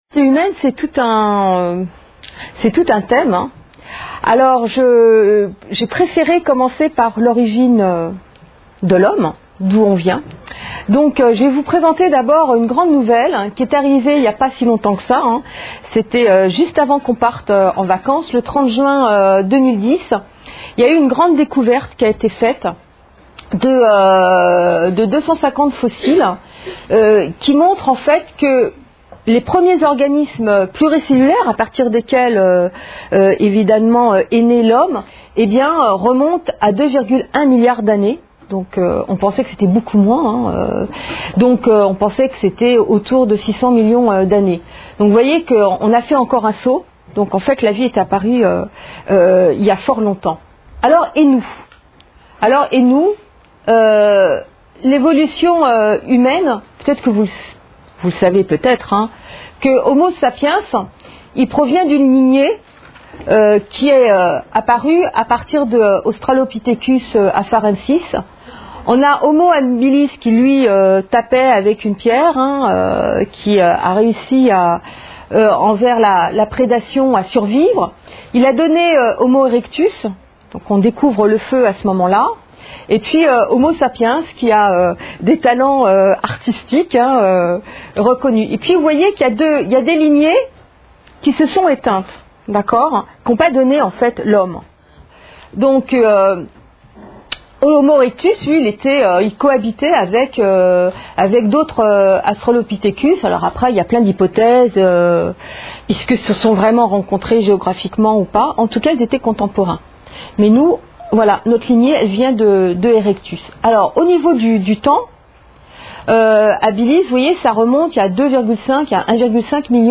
Une conférence de l'UTLS au Lycée La diversité humaine